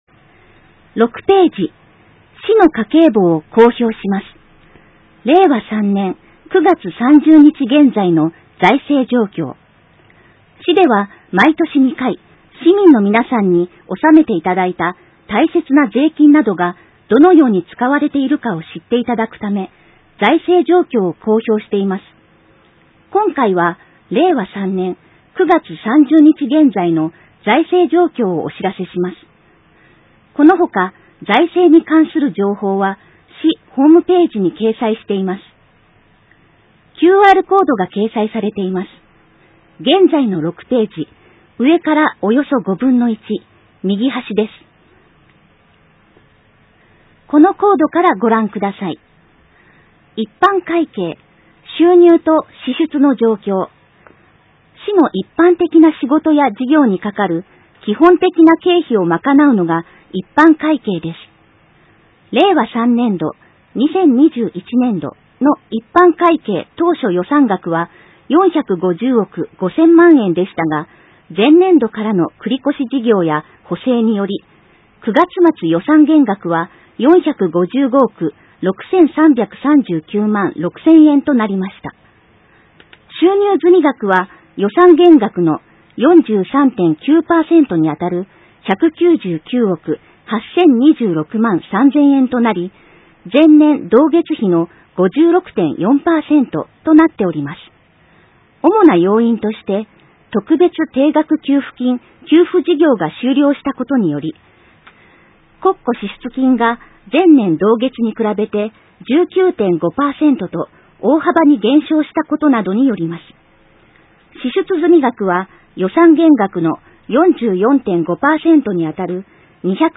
埼玉県朝霞市が発行している広報あさか12月号を、リーディングサークルさんの協力で、音声にしていただいたものです。